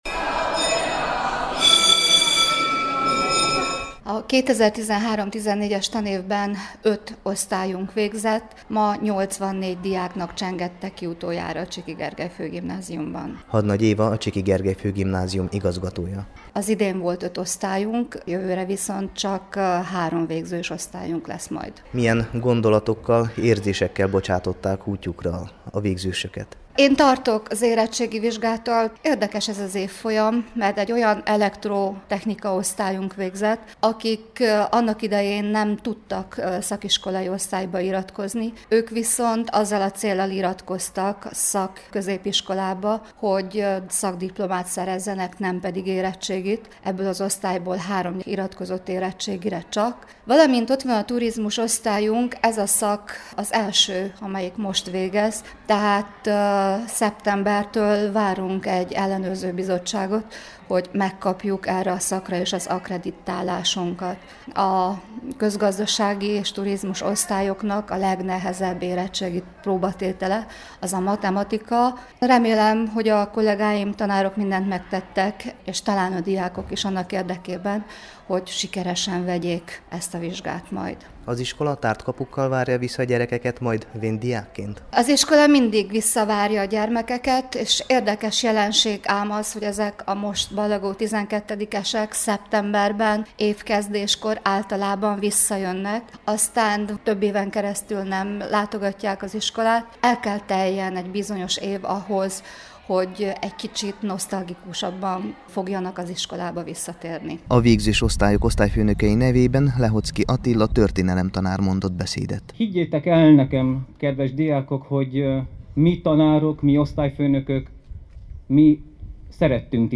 A meghívott elöljárók, a pedagógusok és a diákok mondtak köszöntőket, majd a jelképes kulcsátadással és a jutalmak, érdemoklevelek kiosztásával zárult az ünnepség.